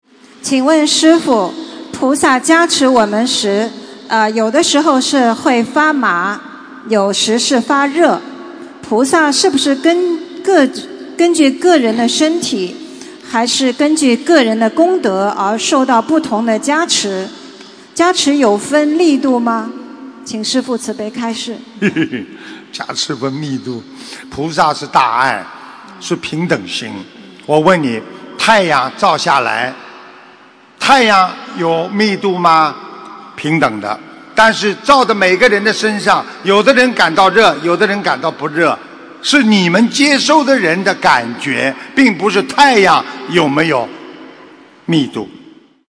为何受到菩萨加持时有不同感应┃弟子提问 师父回答 - 2017 - 心如菩提 - Powered by Discuz!